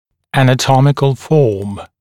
[ˌænə’tɔmɪkl fɔːm][ˌэнэ’томикл фо:м]анатомическая форма